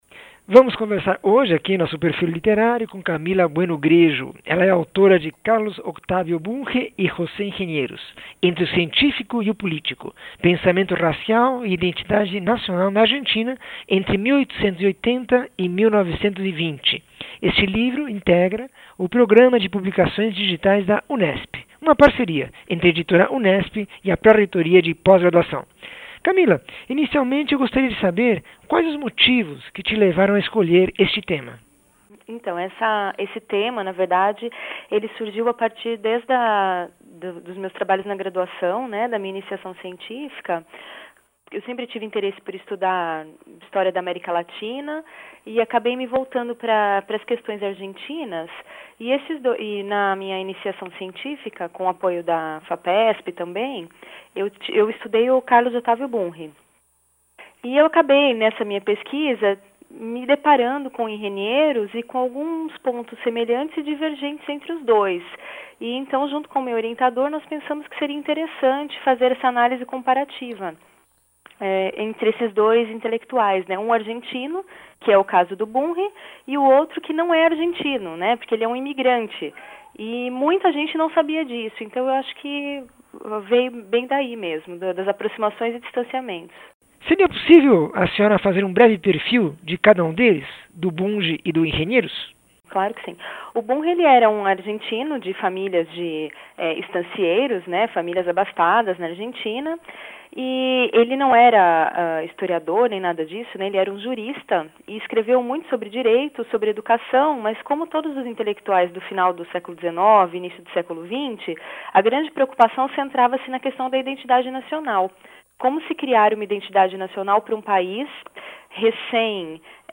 entrevista 641